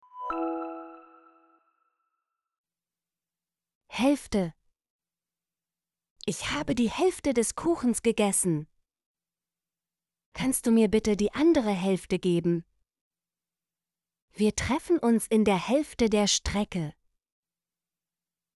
hälfte - Example Sentences & Pronunciation, German Frequency List